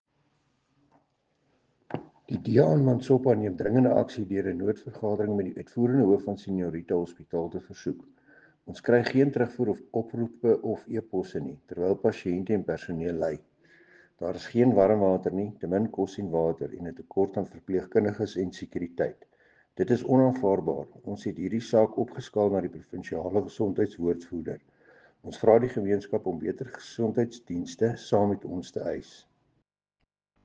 Afrikaans soundbite by Cllr Dewald Hattingh.